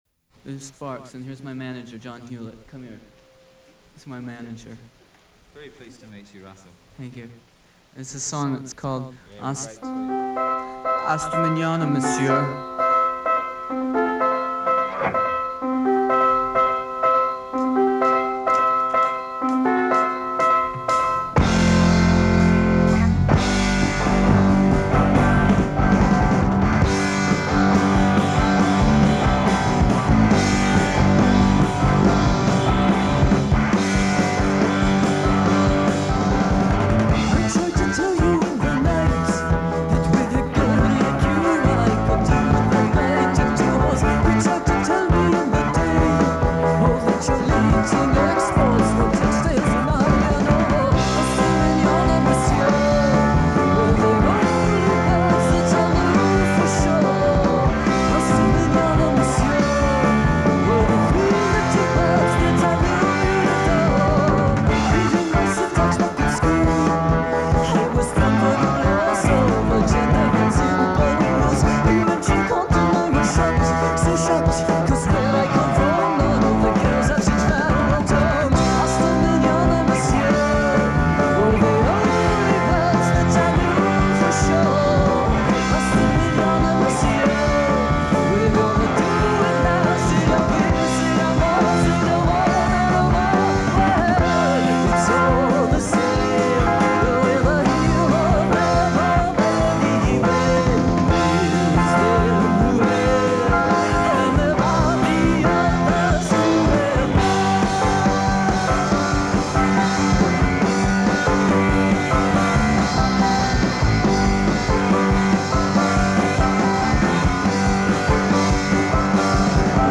Pop Music
Art-Rock
Operatic falsetto